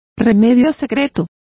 Complete with pronunciation of the translation of nostrum.